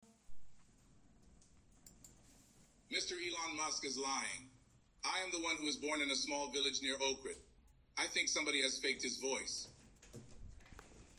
Вака би звучел еден кус разговор меѓу Илон Маск и Морган Фримен.
Morgan-se-javi.mp3